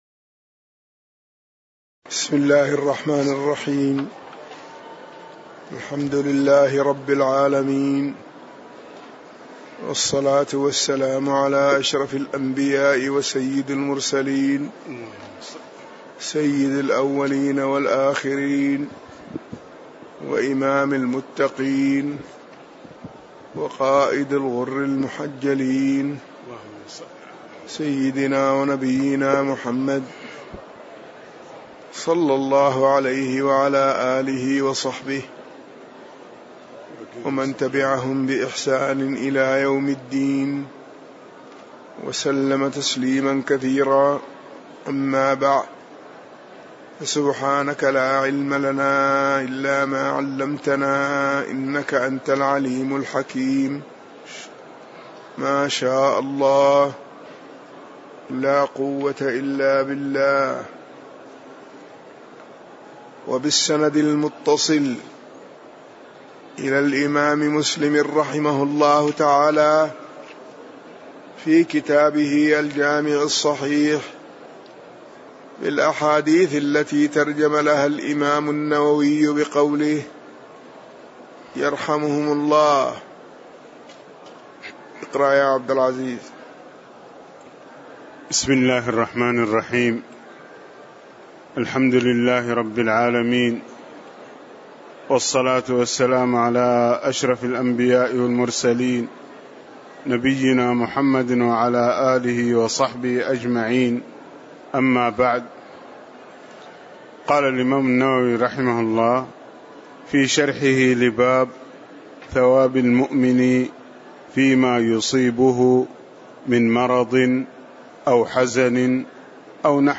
تاريخ النشر ٢٤ محرم ١٤٣٨ هـ المكان: المسجد النبوي الشيخ